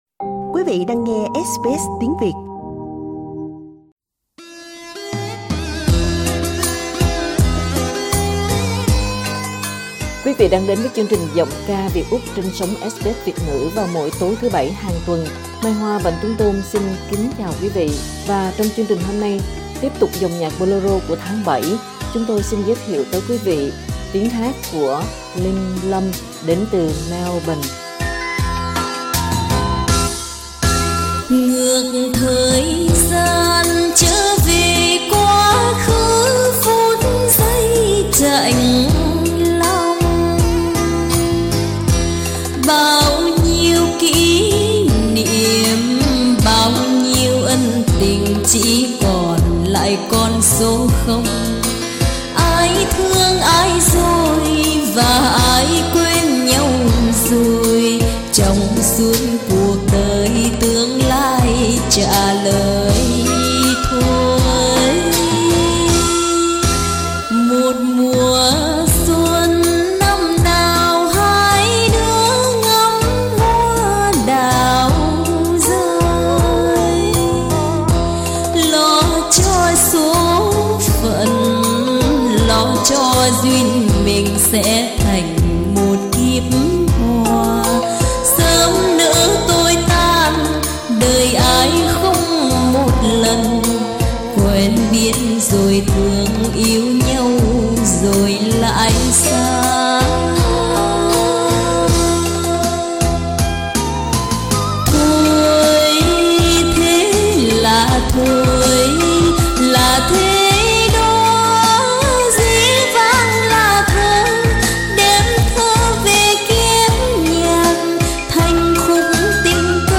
Xinh xắn ngọt ngào, nói giọng Sài Gòn